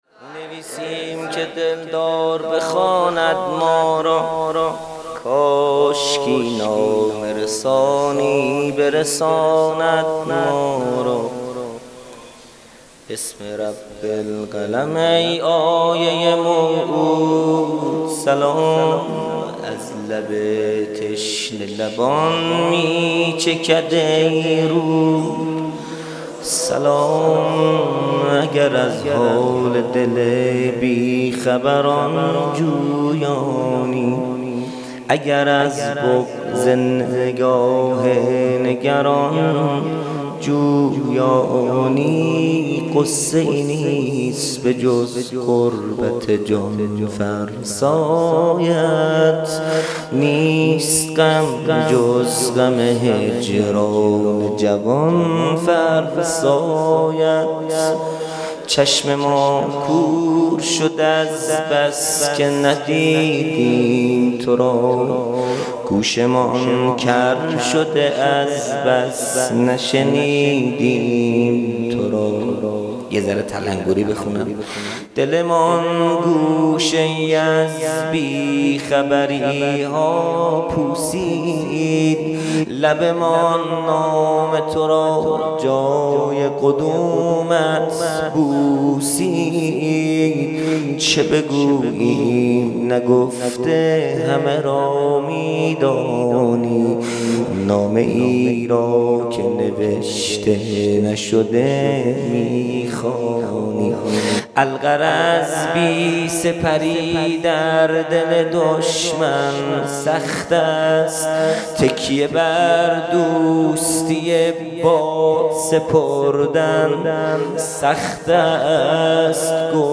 خیمه گاه - هیئت بچه های فاطمه (س) - مدح | مینویسیم که دلدار بخواند ما را | پنجشنبه۲۶ اسفند۱۴۰۰